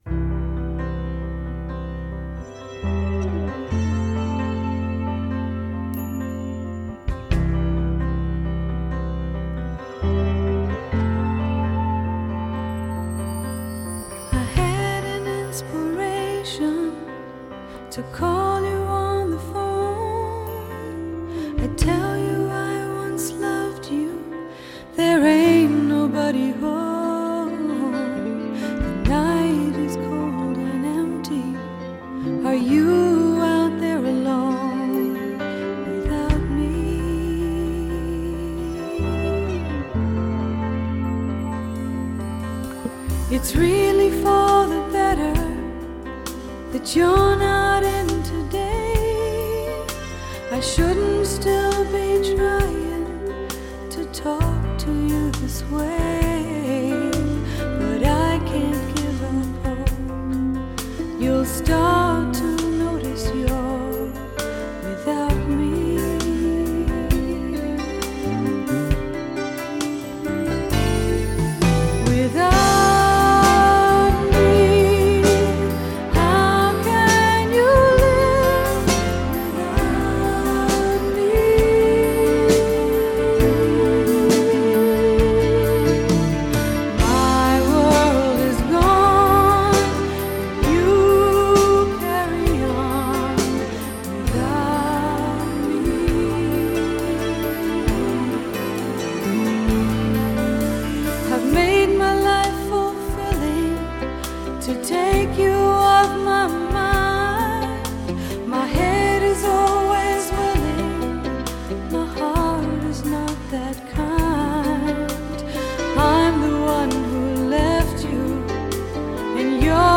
★1990 年代人手一張的女聲測試片，以原始母帶精心重製，收錄三首原版未收錄之新曲！